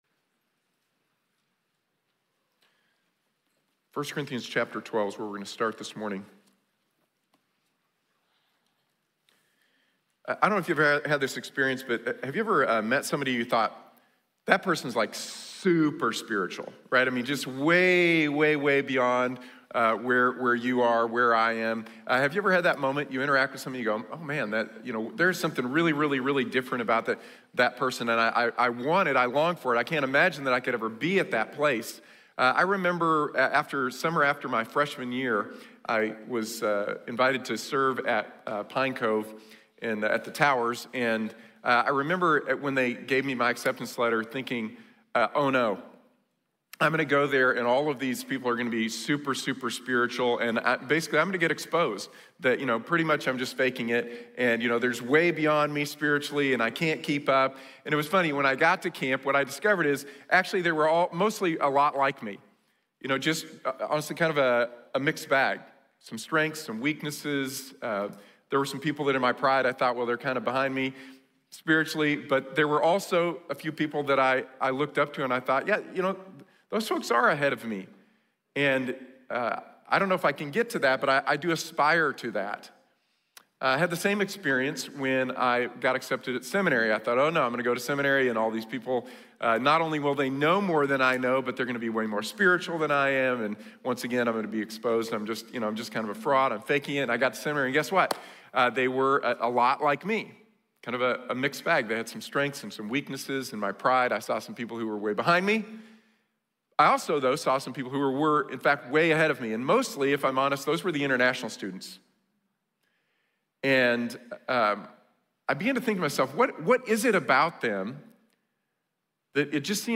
We are the body of Christ | Sermon | Grace Bible Church